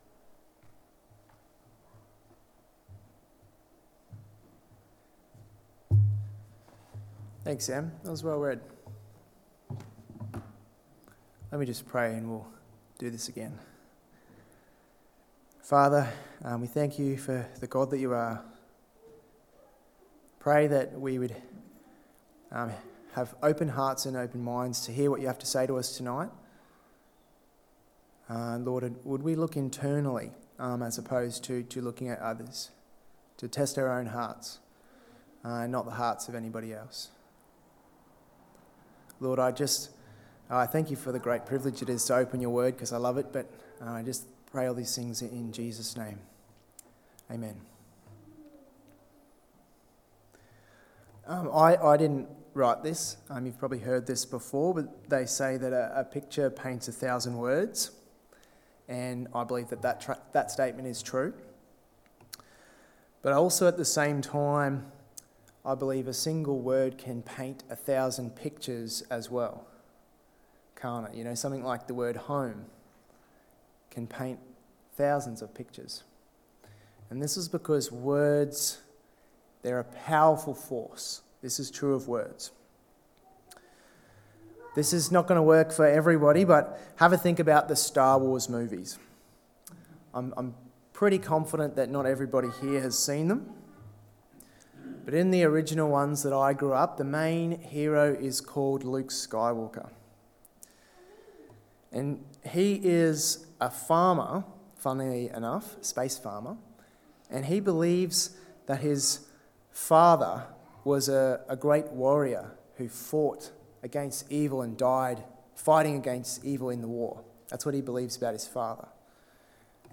Sermons | Tenthill Baptist Church